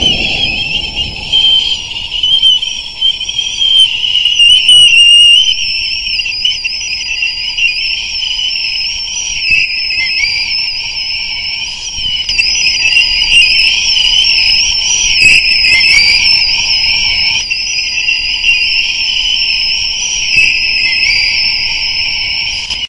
描述：清晨站在靠近嘈杂高速公路的森林里，听着数百只歌唱的小鸟。
Tag: 歌曲 叽叽喳喳